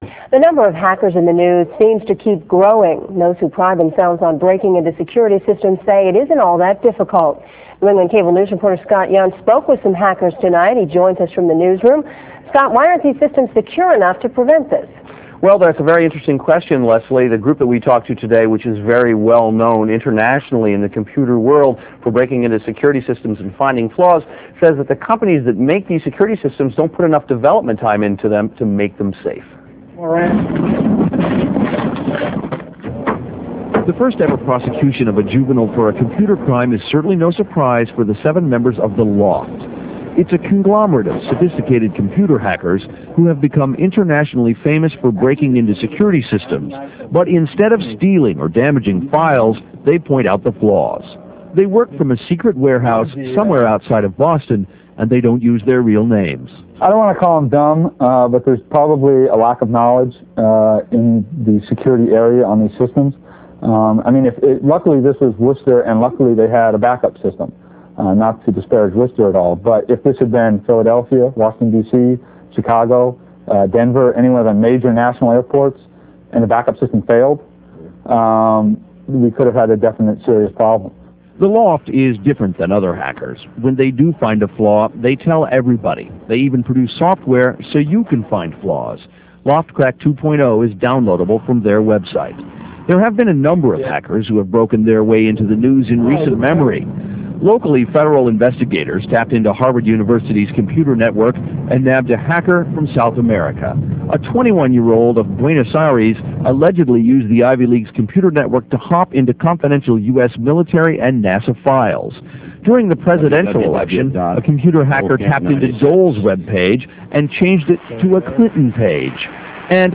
aired a segment emphasizing the L0pht's technology reclaimation efforts that played all day on March 20, 1997.